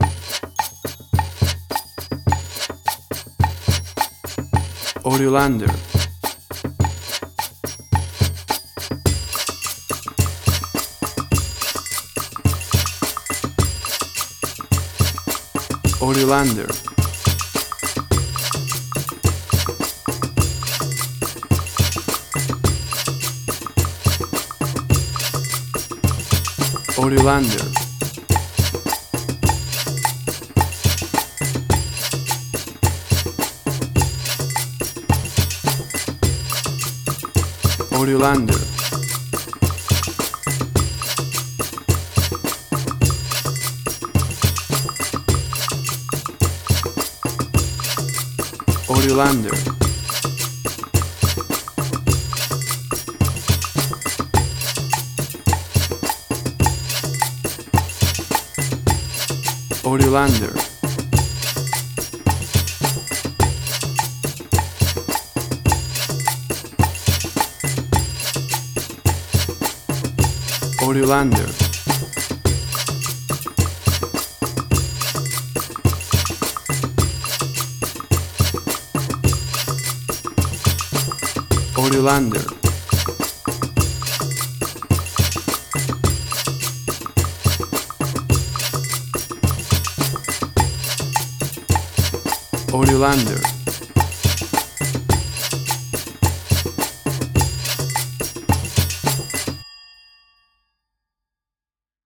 Cumbia mood very percusive and a bit strange
Tempo (BPM): 106